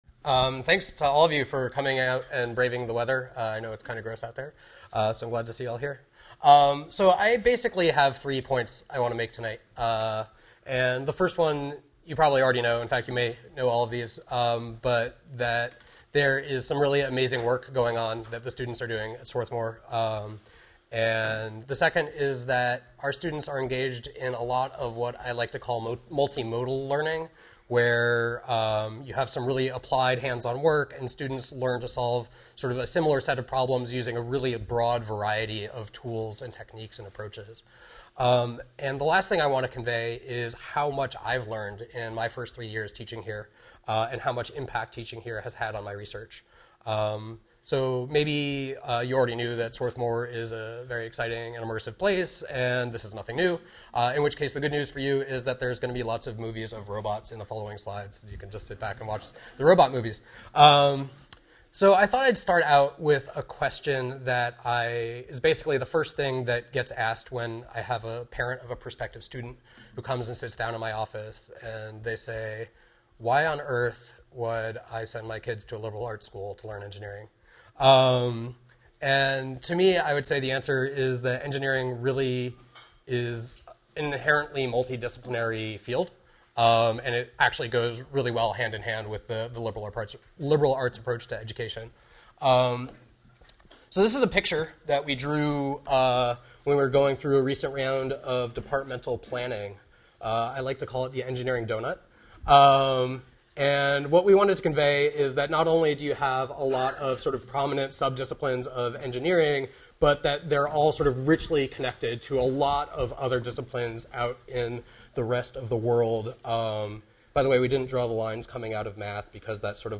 During Alumni Weekend 2013